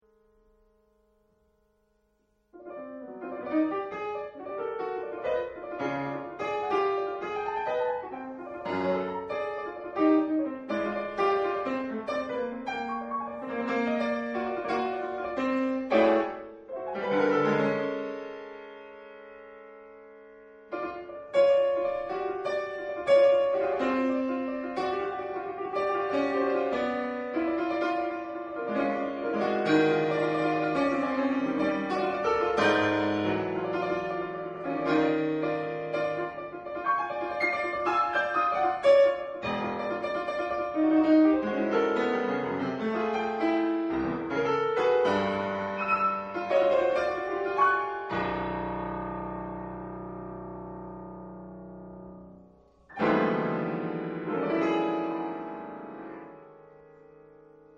for Piano